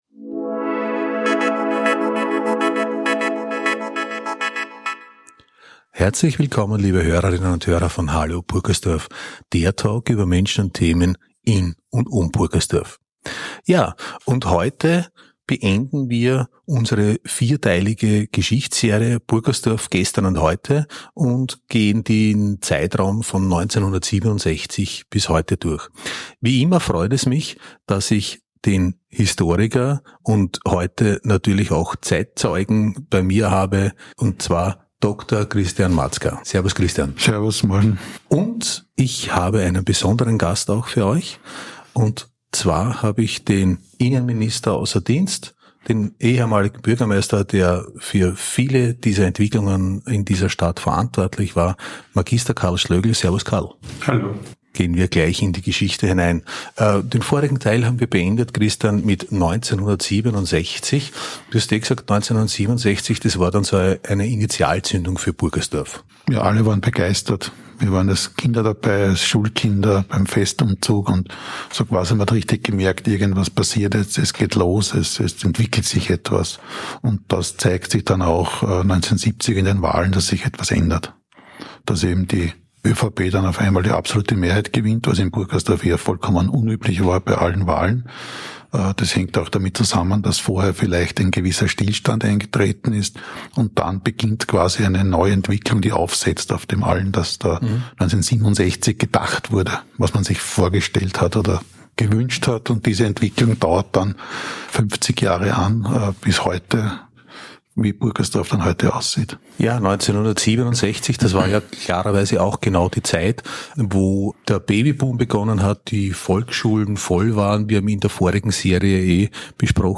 Hallo Purkersdorf · E102 Purkersdorf GESTERN & HEUTE_Teil 4 Play episode March 18 41 mins Bookmarks Episode Description HALLO PURKERSDORF Der Talk über Menschen und Themen in und um Purkersdorf Das Interview behandelt die Entwicklung der Stadt Purkersdorf aus historischer und politischer Perspektive.